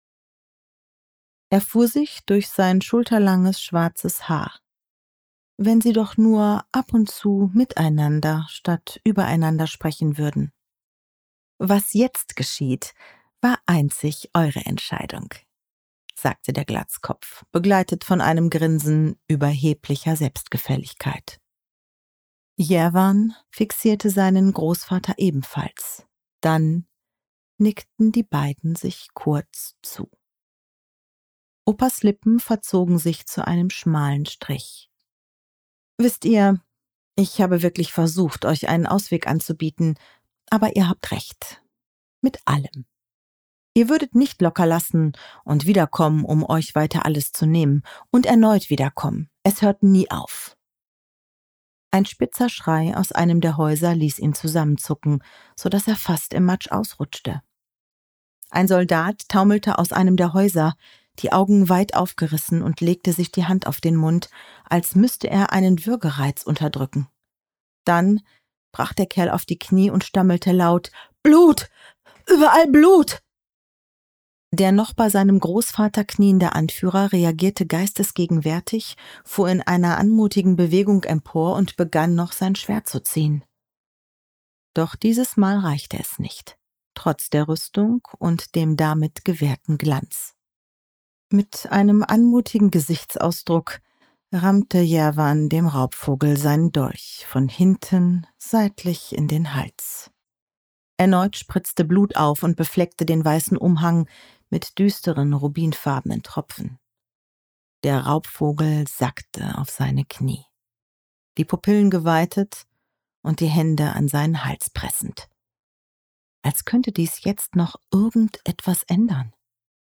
Willkommen in der Hörbuch Welt!
Sprecherin:
Sie trägt die düstere Atmosphäre mit Klarheit und Intensität und gibt den unterschiedlichen Figuren spürbare Tiefe, ohne sie zu vereinfachen.